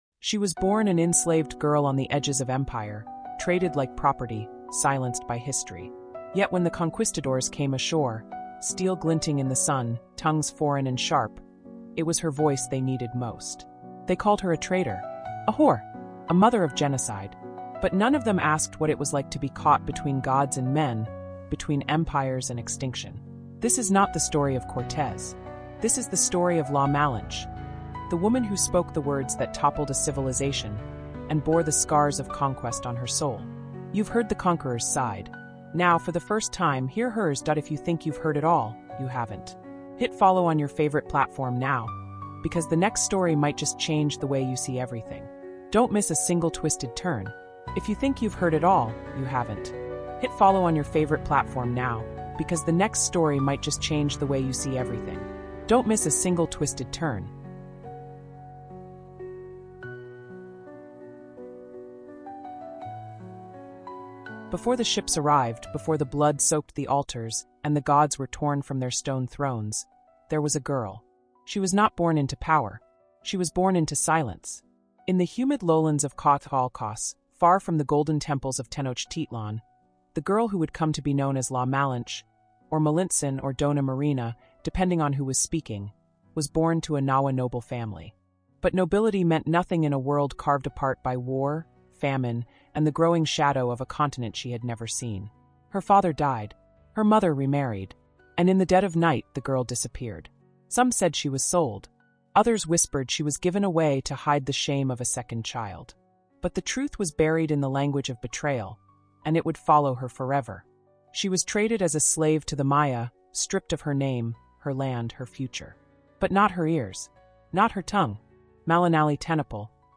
CARIBBEAN HISTORY: La Malinche — The Woman Who Changed the Fate of Empires is an emotionally immersive, fact-based audiobook documentary exploring the extraordinary life of one of the most misunderstood women in the Americas. La Malinche, born into nobility but enslaved and ultimately transformed into a pivotal cultural translator, strategist, and intermediary during the Spanish conquest of the Aztec Empire, reshaped the destiny of nations without ever wielding a sword.